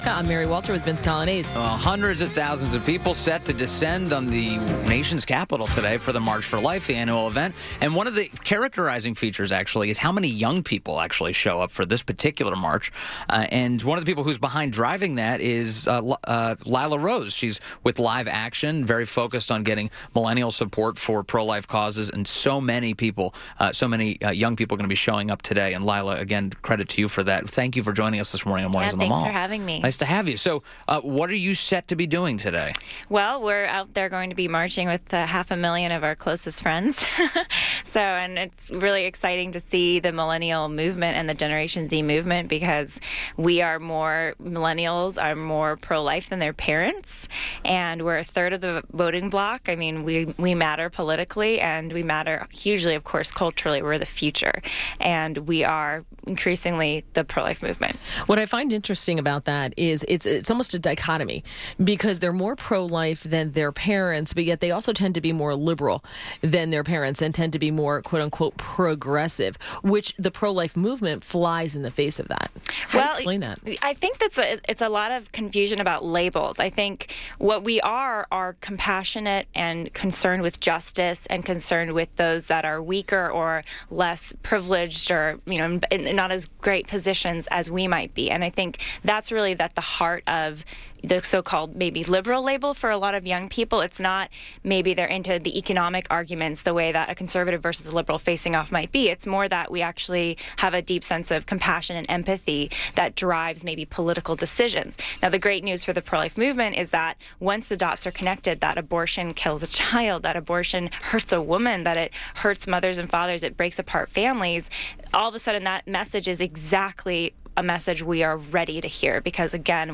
INTERVIEW - LILA ROSE - Pro-Life activist and the founder of Live Action - IN PERSON IN STUDIO